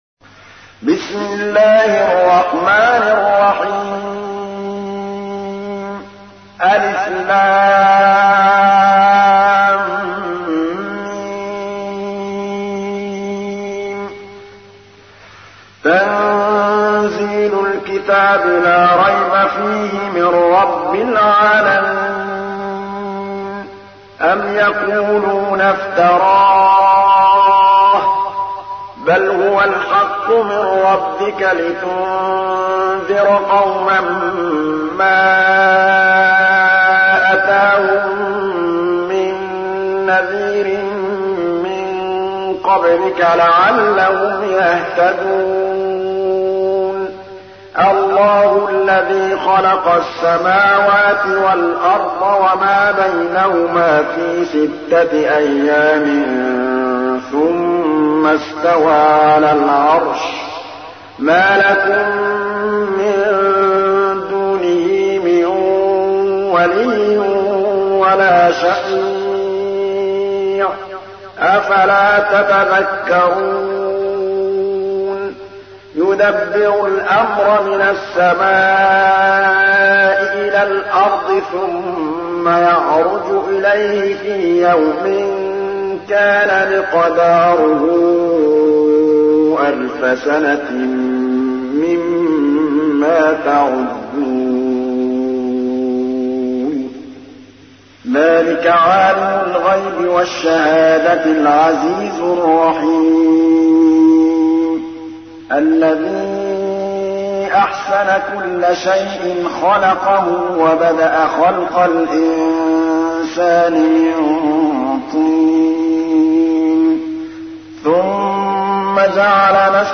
تحميل : 32. سورة السجدة / القارئ محمود الطبلاوي / القرآن الكريم / موقع يا حسين